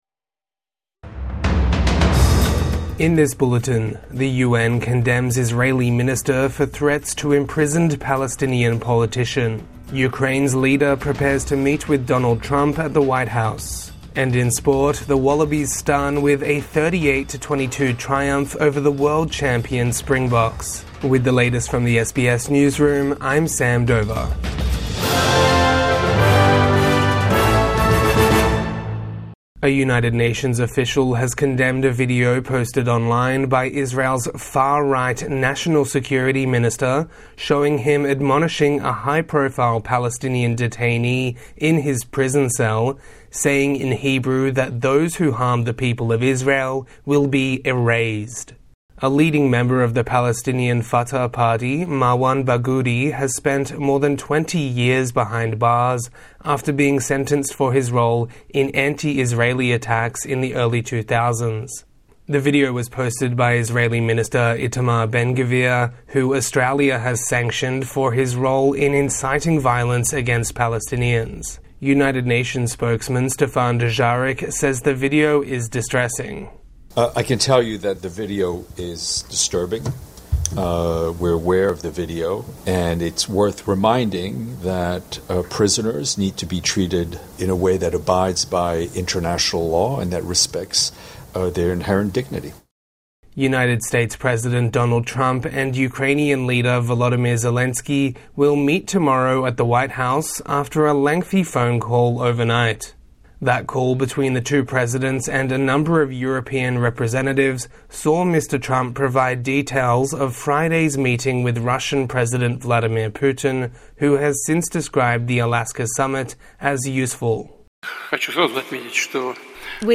SBS News Podcasts